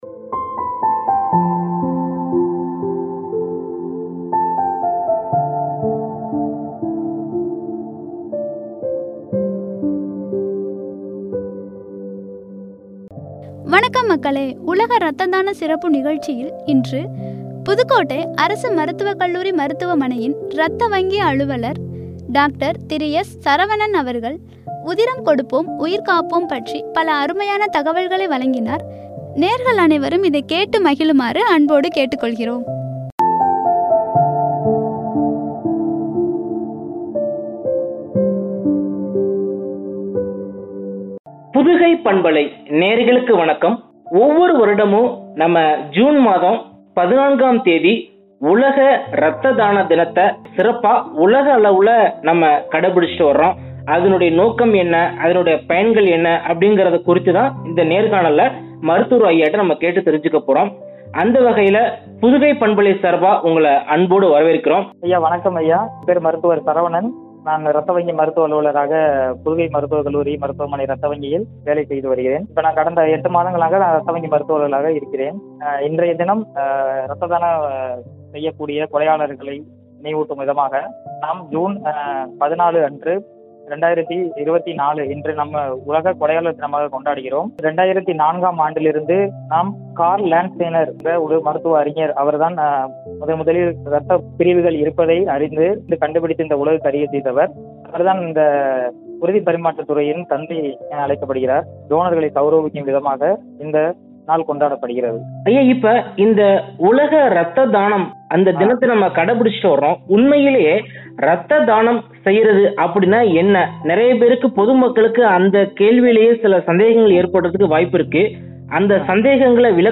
“ என்னும் தலைப்பில் வழங்கிய உரையாடல்.